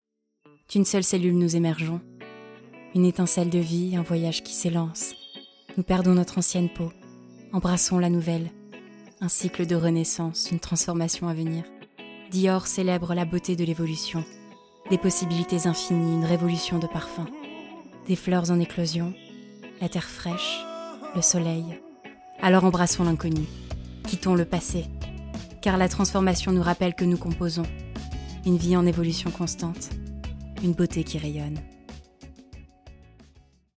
Voix off projet DIOR - itwc production
9 - 40 ans - Soprano